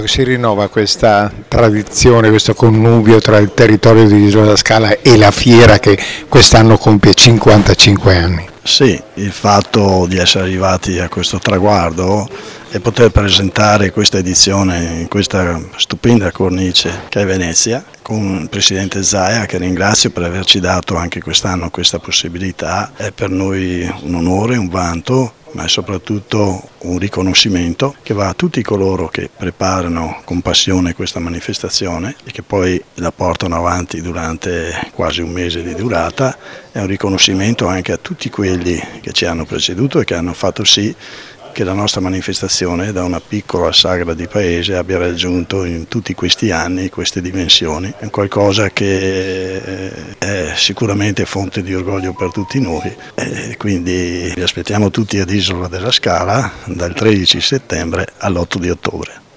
Le dichiarazioni raccolte dal nostro corrispondente
Luigi Mirandola, Sindaco di Isola della Scala
Luigi-MIrandola-Sindaco-di-Isola-della-Scala.wav